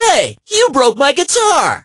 poco_die_02.ogg